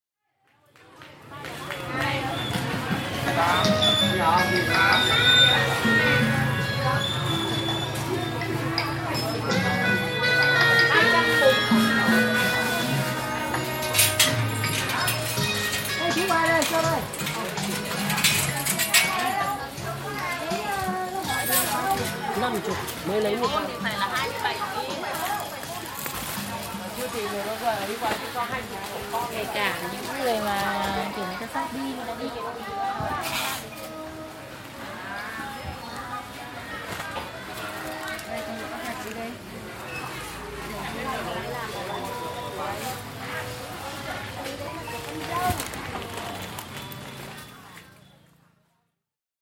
Recorded in Hanoi, Vietnam